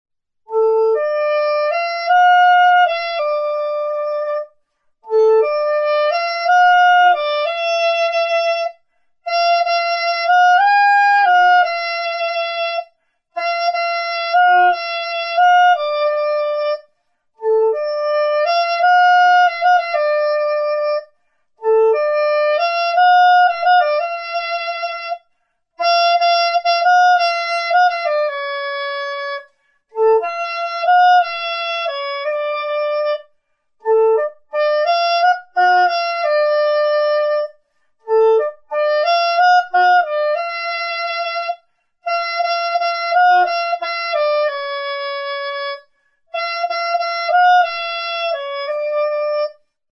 Aérophones -> Anches -> Simple battante (clarinette)
Enregistré avec cet instrument de musique.
Fa tonalitatean afinaturik dago.